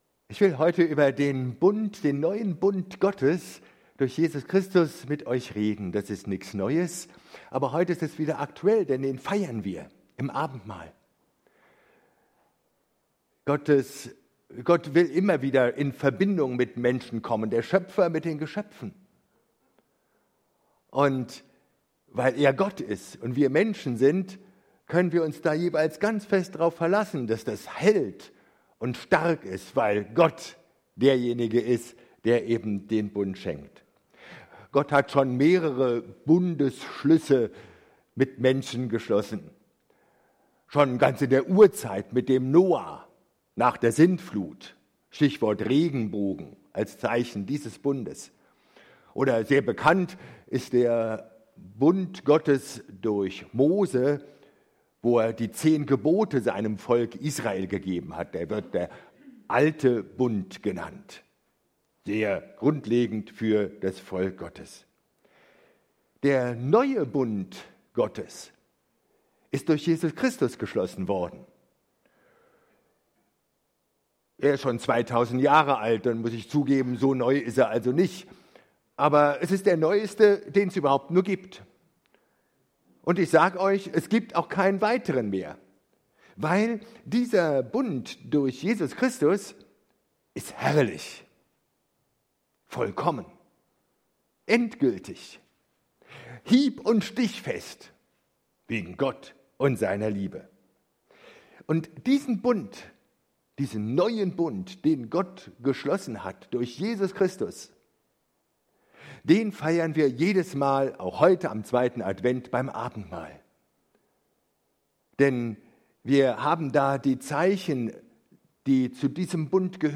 Predigt vom 21.01.2024